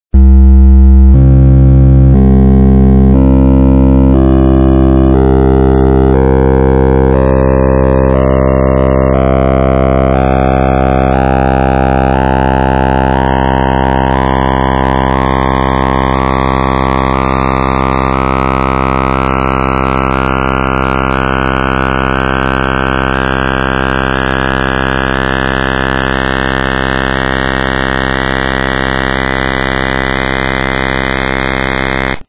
Schließlich runden Überlagerungen der ersten 4 bzw. 16 bzw. 32 Partialtöne in den Hörbeispielen